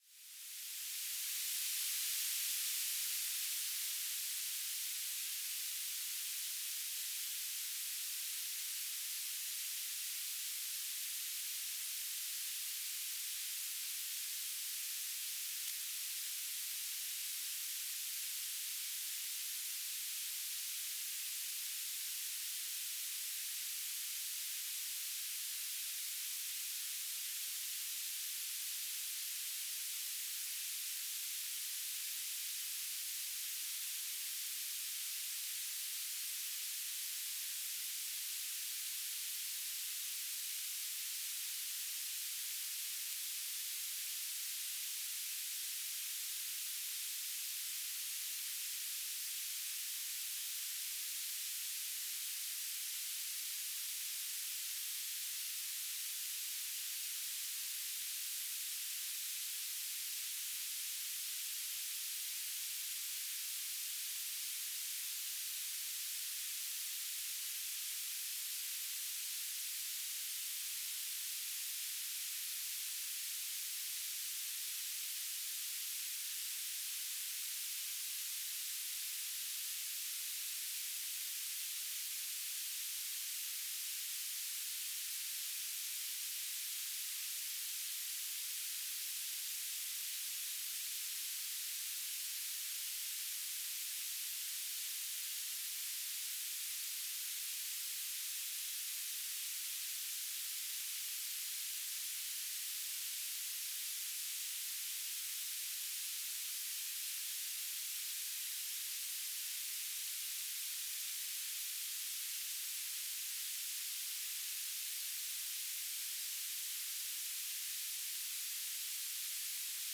"transmitter_description": "Mode U - BPSK9k6 G3RUH - Beacon",
"transmitter_mode": "BPSK",